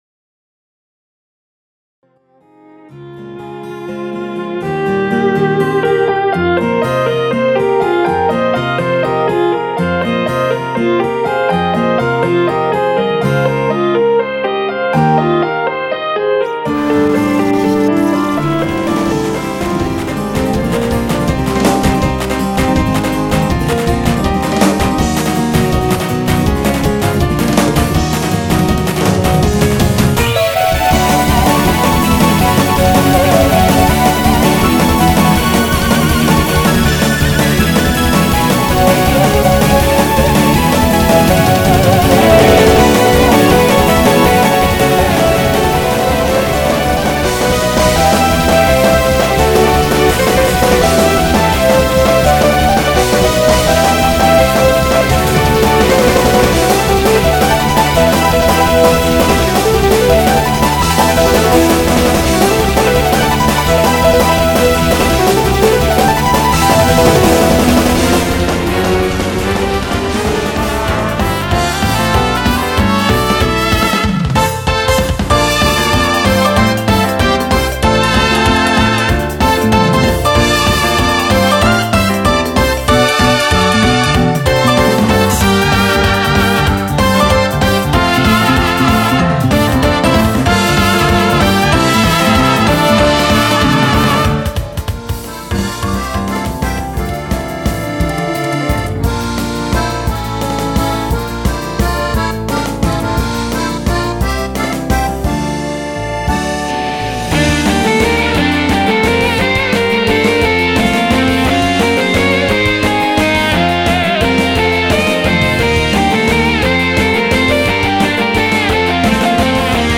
크로스페이드 데모 mp3